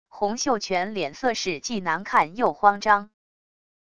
洪秀全脸色是既难看又慌张wav音频生成系统WAV Audio Player